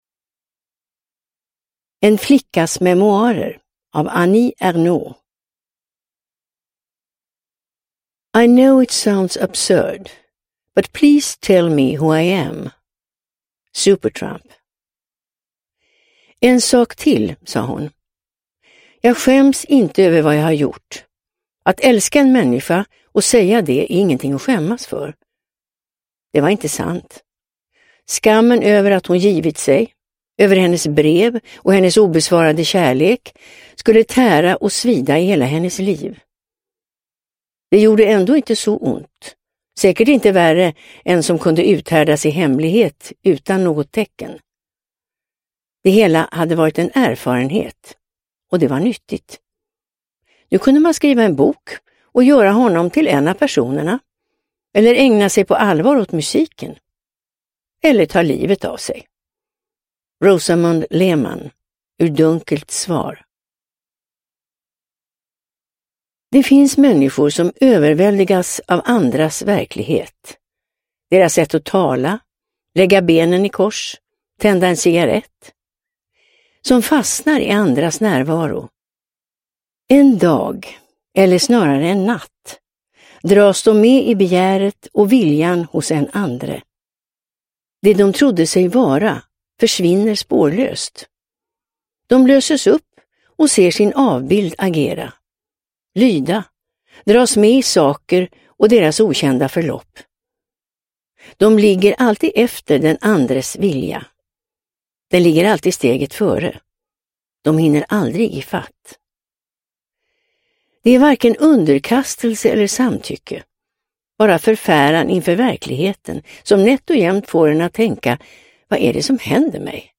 En flickas memoarer – Ljudbok – Laddas ner